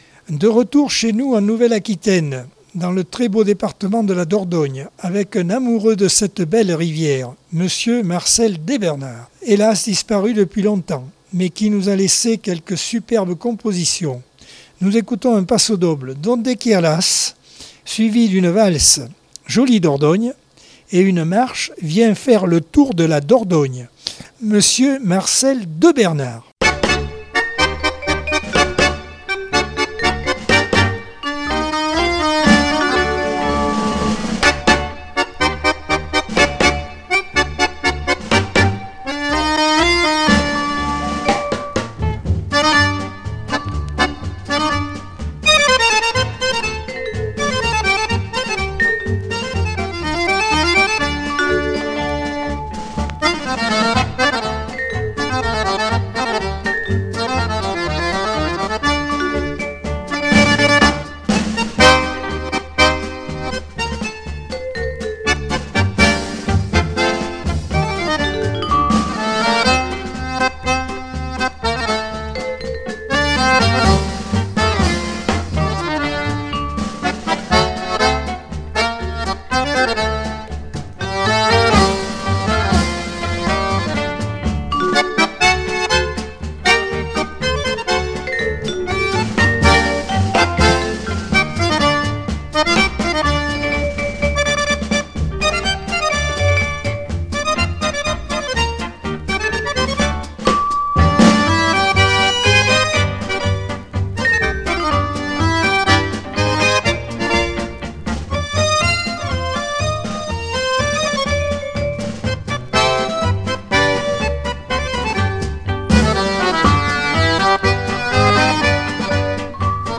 Accordeon 2022 sem 39 bloc 2 - Radio ACX